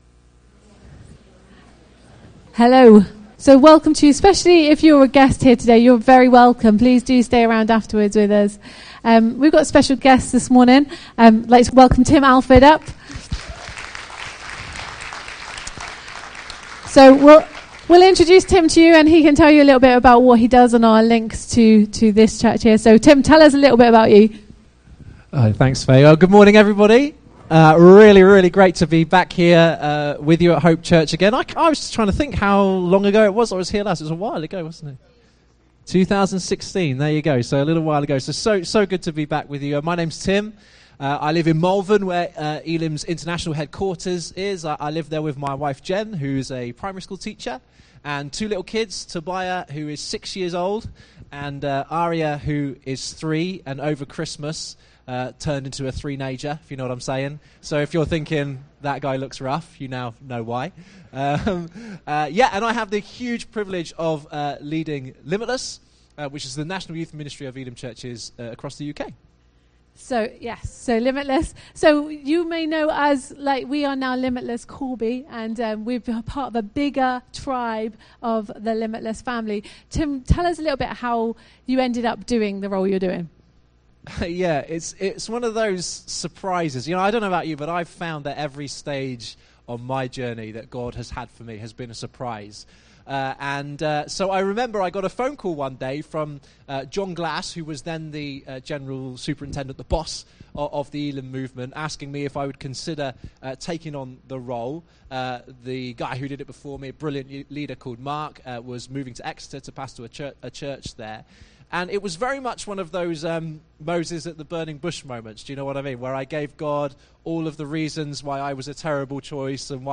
Jan 12, 2020 Passing the Baton MP3 SUBSCRIBE on iTunes(Podcast) Notes 2 sermons for the price of 1!